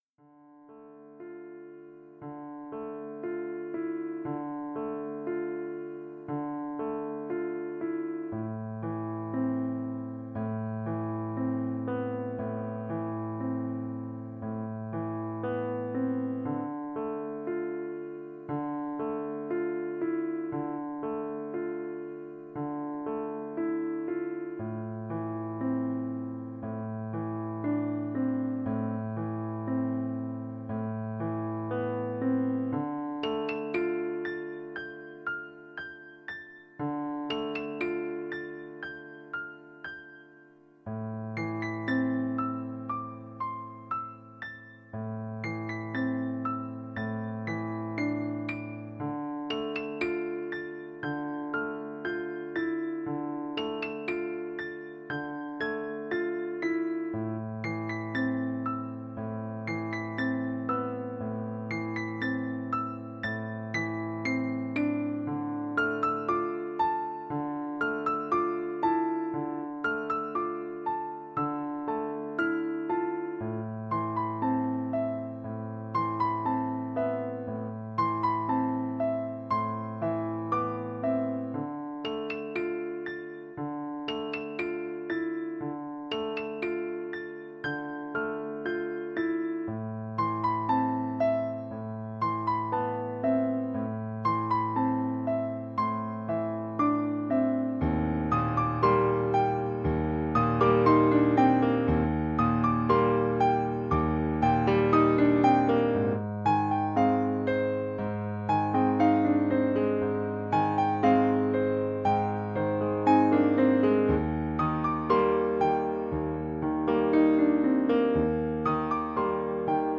她或能令你進入放鬆狀態， 故切勿於駕駛或操作機器時收聽！ Level 1 音樂 ： 下載 ： Level 1 音樂 注意 ：這首音樂原只是給小弟自己收聽的，小弟 並不負責 閣下使用她，而帶來的任何結果。